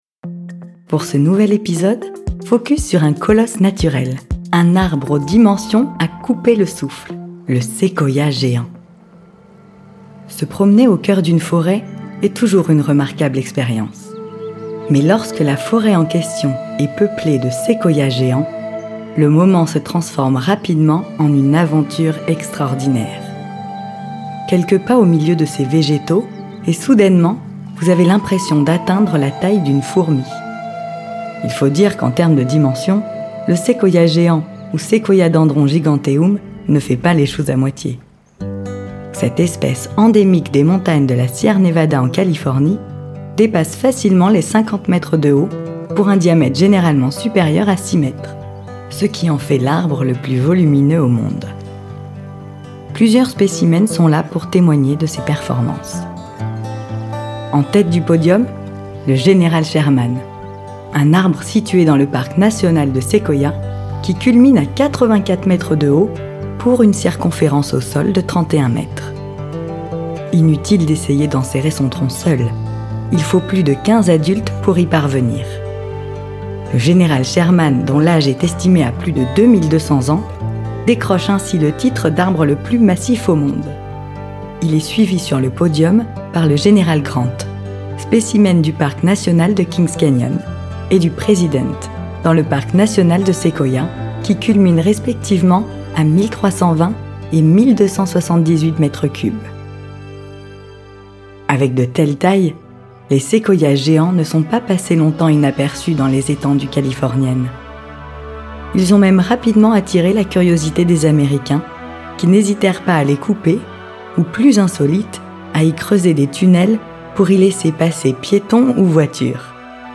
Voix off Documentaire